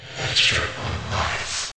get_extra_life.ogg